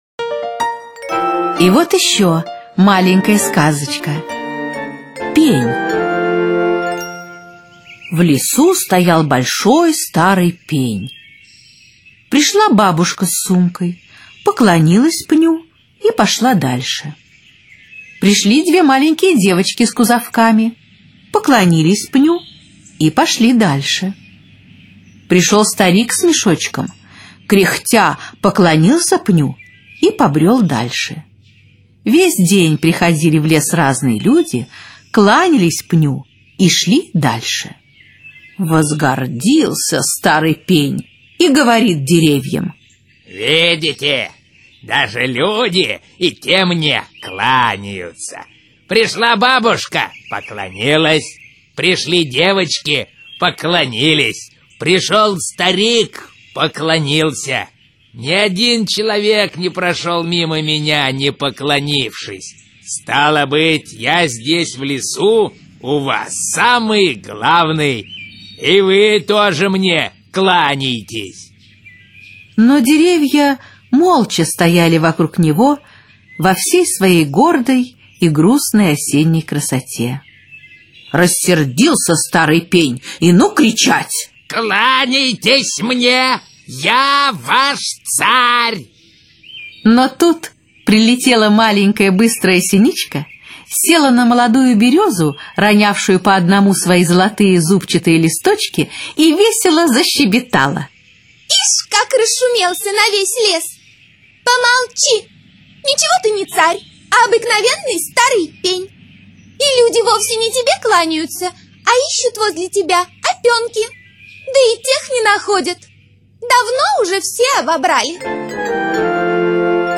Пень - аудиосказка В.П. Катаева